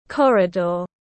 Corridor /ˈkɒr.ɪ.dɔːr/